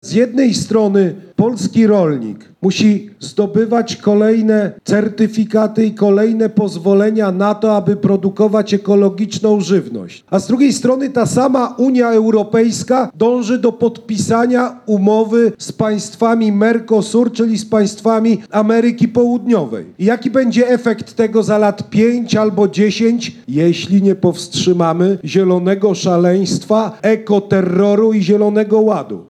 – Zawsze będę dbał o interesy polskich rolników – deklarował na spotkaniu z mieszkańcami.